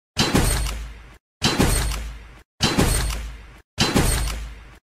fortnite-headshot-sound-effect-fast-loop-download-no-copyright.mp3